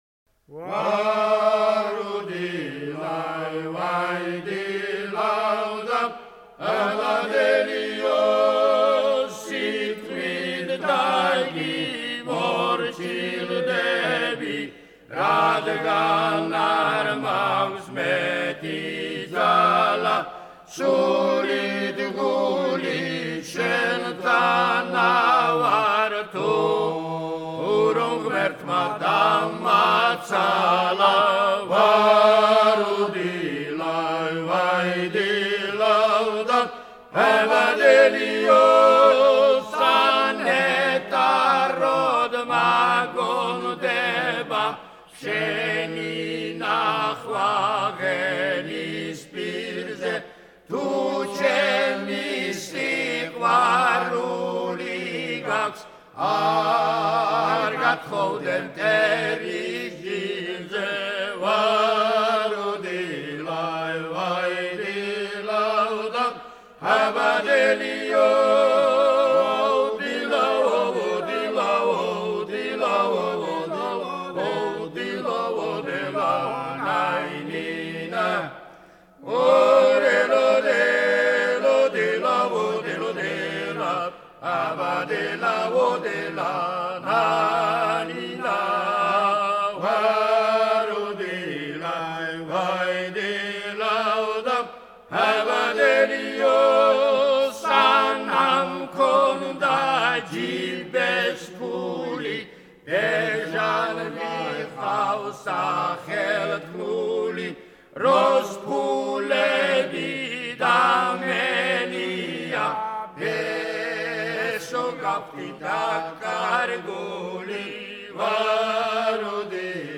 Georgian Folklore